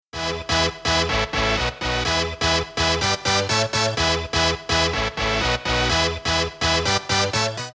заводные
dance
без слов
90-е
энергичные
Melodic
Euro